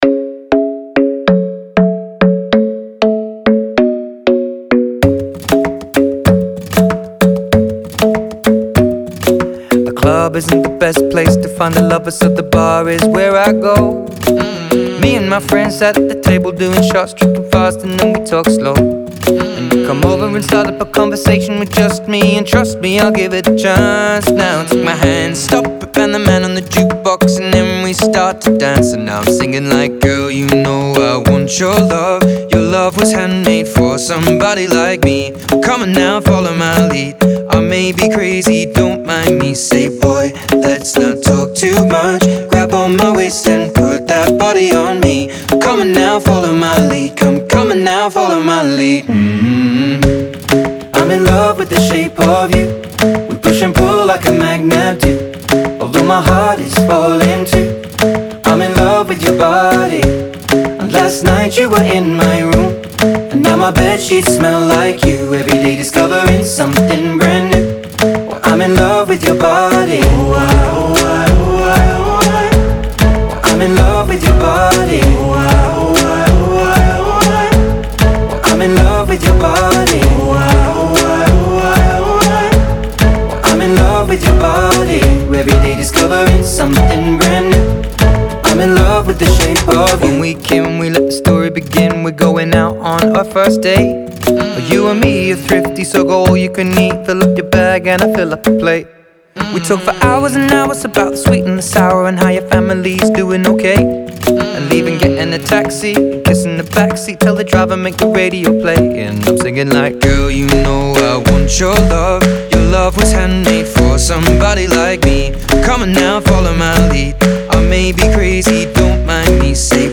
Зарубежные новинки 2025PopDance